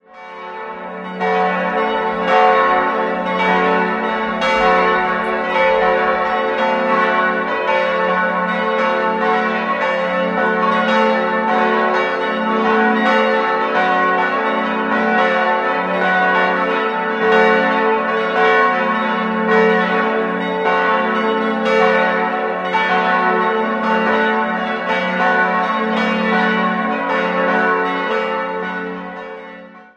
Marienglocke fis' 940 kg 1997 Rudolf Perner, Passau Elisabethglocke a' 560 kg 1997 Rudolf Perner, Passau Zachariasglocke h' 420 kg 1997 Rudolf Perner, Passau Johannes-der-Täufer-Glocke cis'' 310 kg 1997 Rudolf Perner, Passau Josefsglocke e'' 150 kg 1997 Rudolf Perner, Passau Johannes-Evangelist-Glocke fis'' 105 kg 1997 Rudolf Perner, Passau